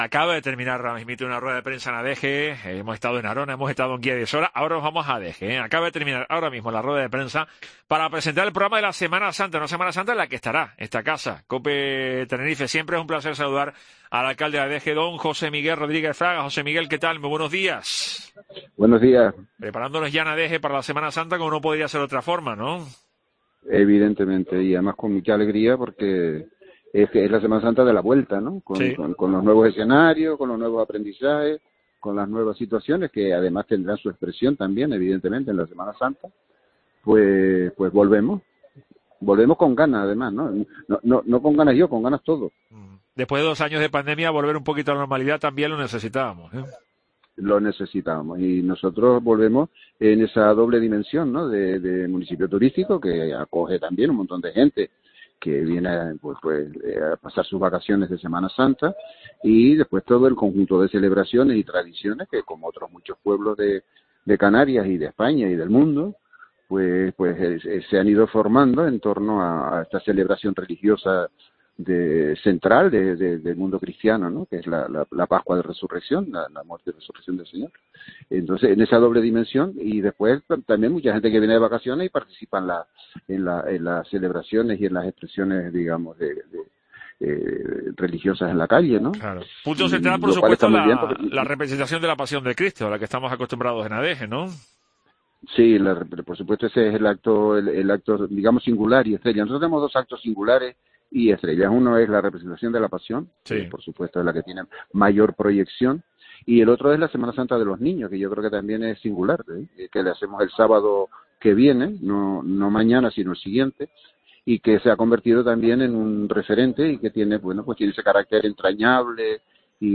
José Miguel Rodríguez Fraga, alcalde de Adeje, invita a participar de la Semana Santa 2022